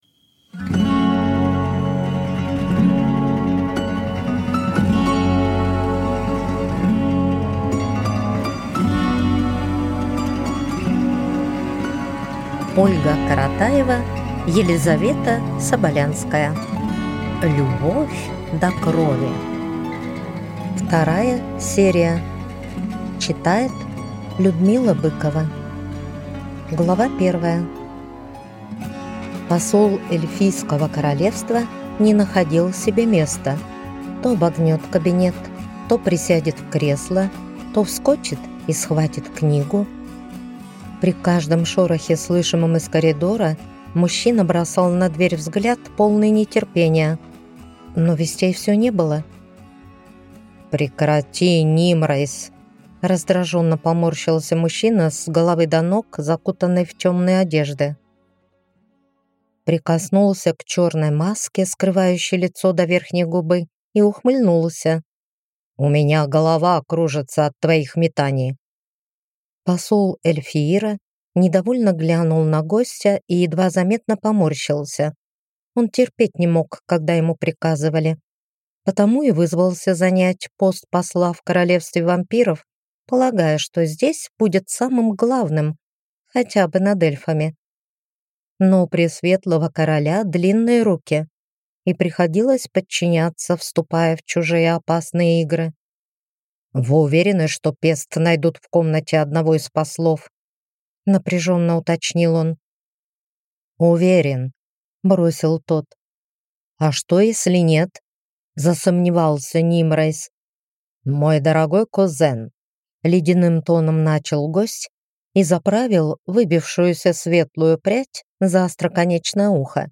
Аудиокнига Любовь до крови 2 | Библиотека аудиокниг